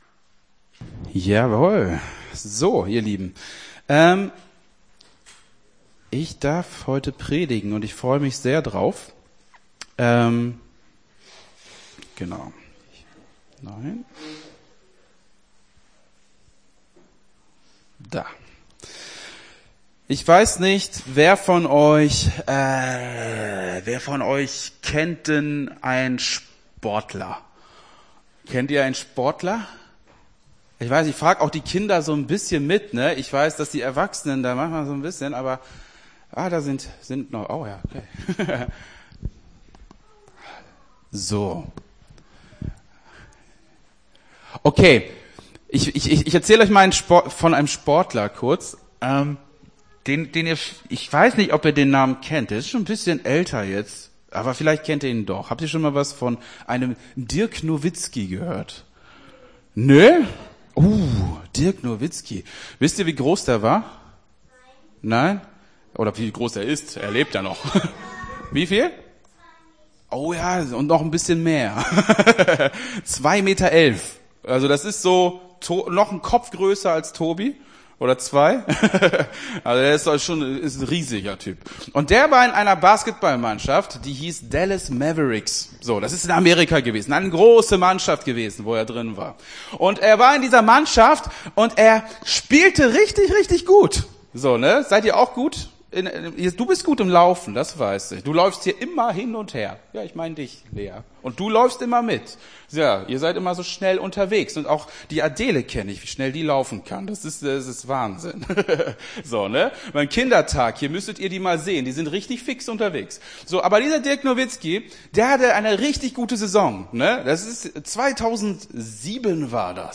Gottesdienst 09.04.23 - FCG Hagen